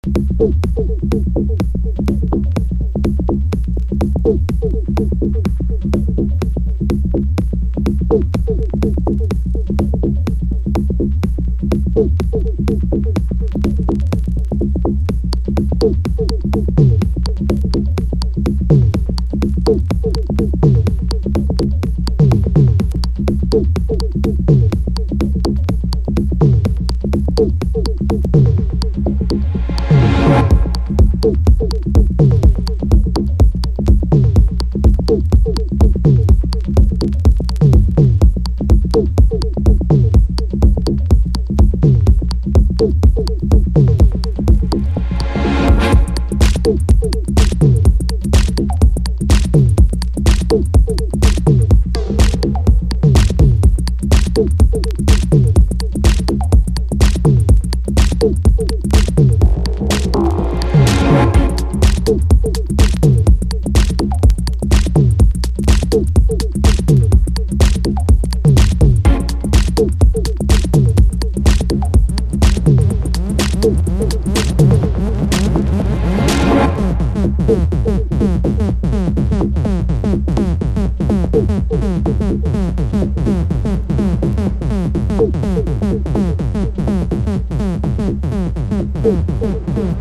ファンキー・テック・ハウス！！